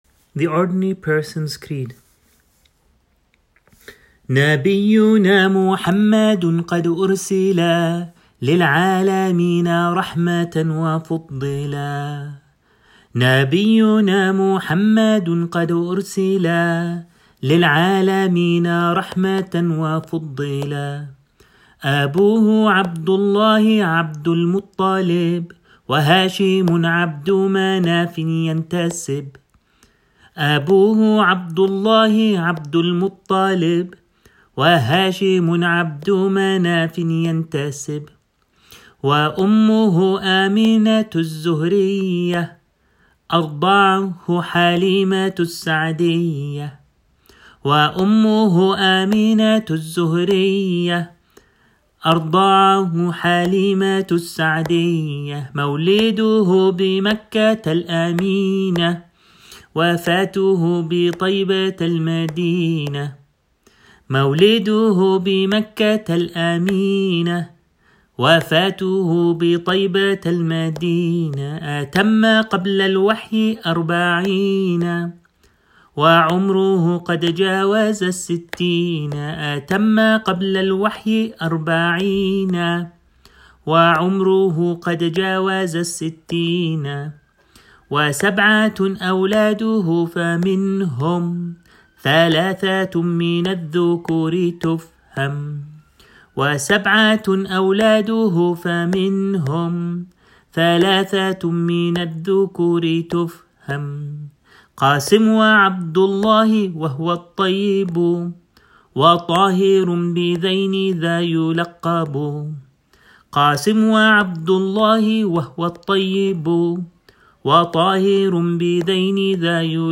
The words from the traditional qasida can be downloaded below:
recitation of the qasida